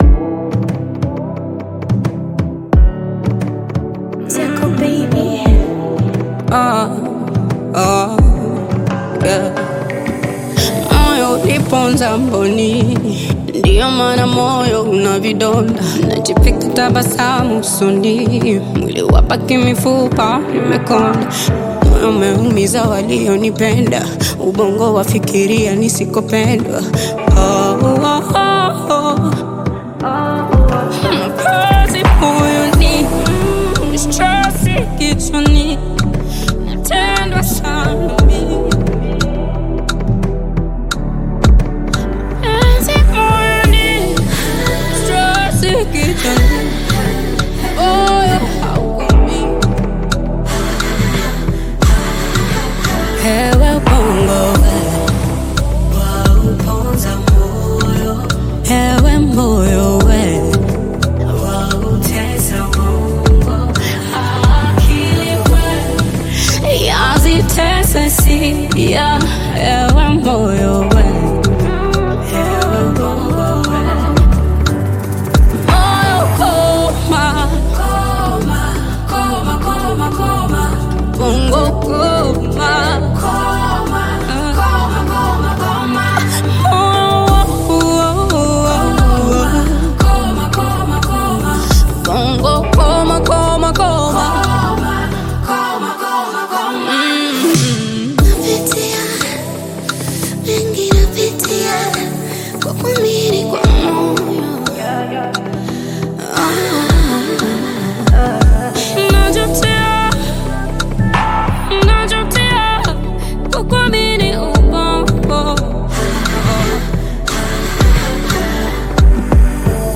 Tanzanian Bongo Flava
African Music